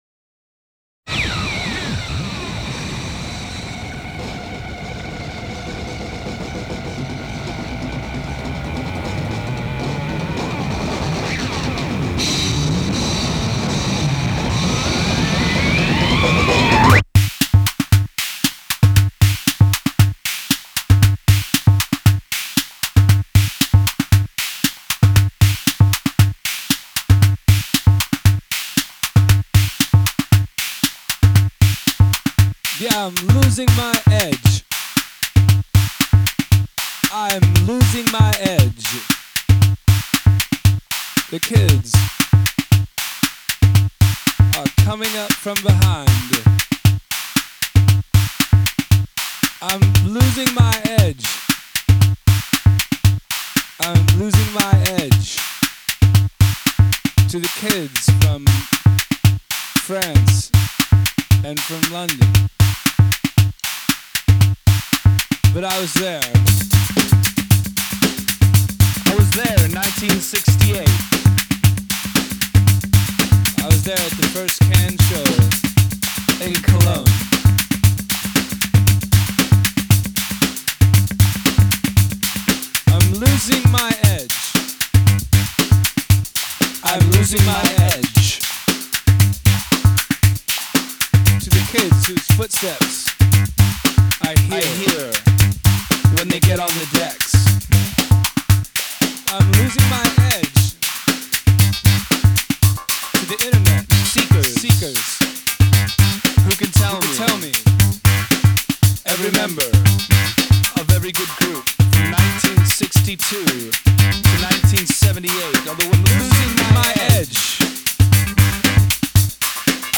close-up yet widescreen
attention-grabbing half-spoken vocal is both rapt and rapped
delivered deadpan and with at least half a tongue in cheek